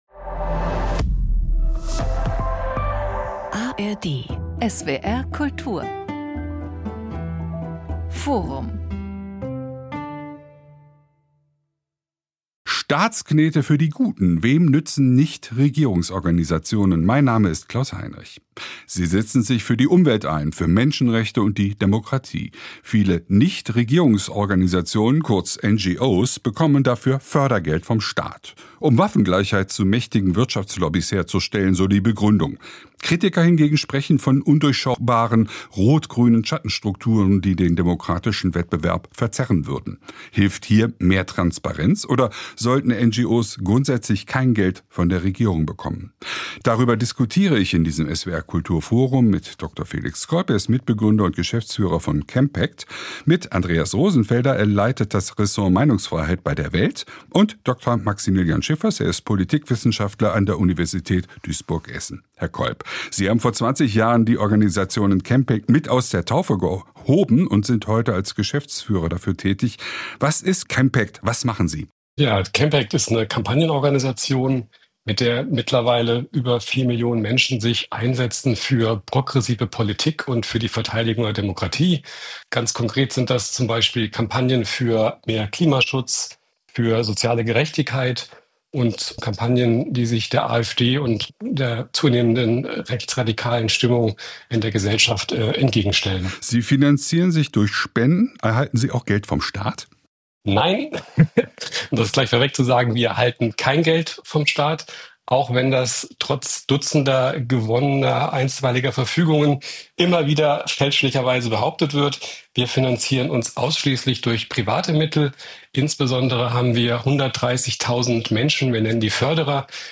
diskutiert